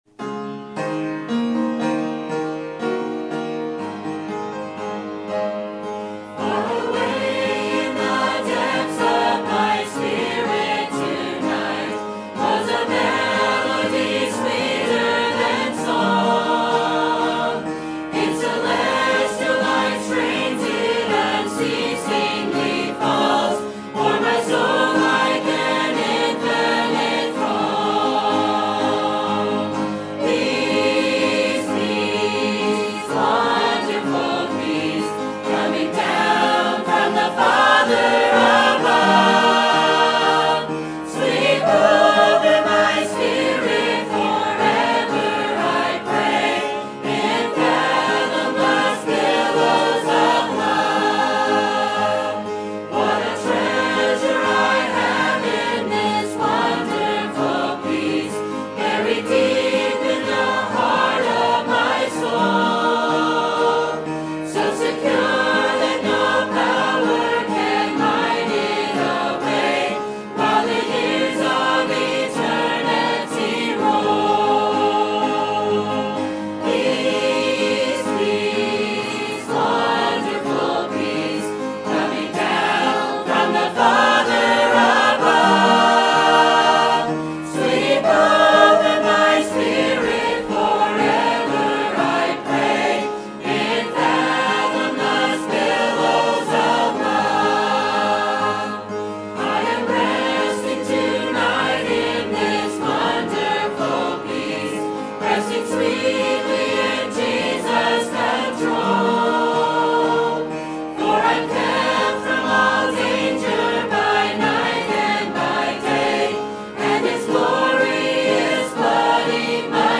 Sermon Topic: Basic Bible Truths Sermon Type: Series Sermon Audio: Sermon download: Download (27.78 MB) Sermon Tags: Ephesians Kingdom